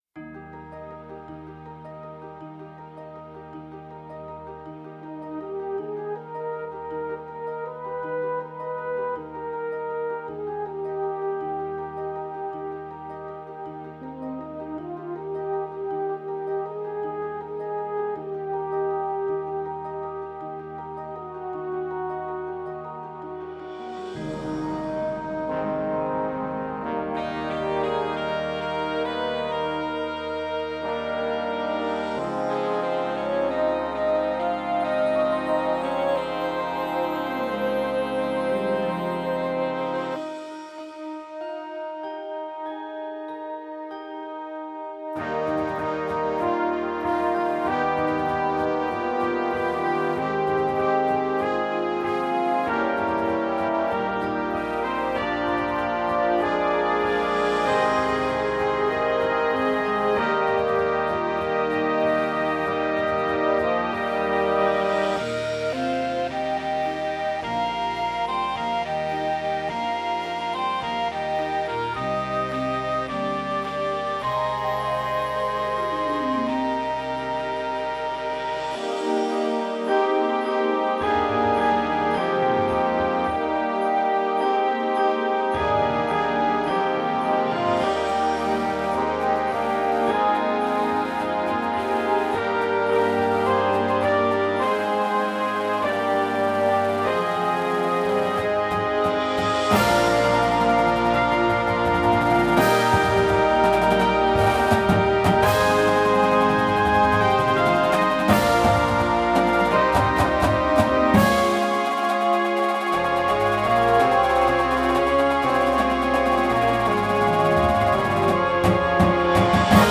Winds and Full Percussion